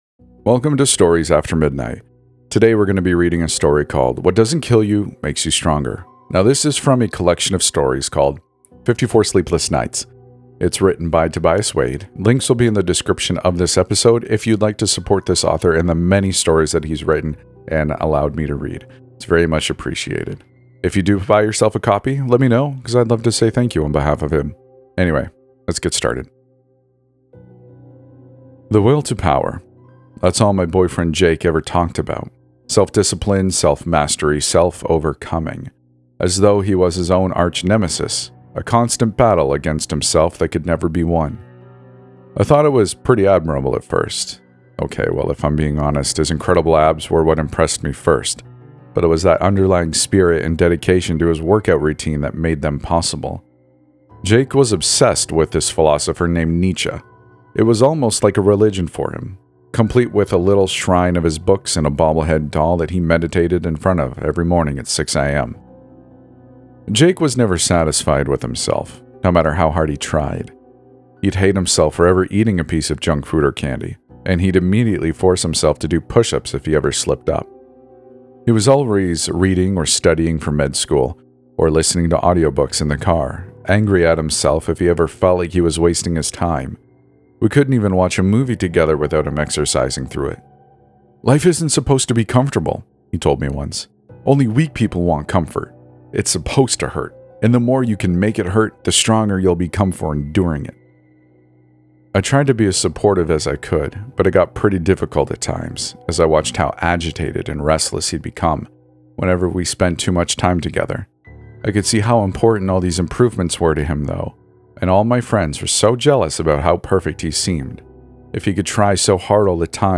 Story: "What Doesn’t Kill You Makes You Stronger" from the book 54 Sleepless Nights Grab your copy!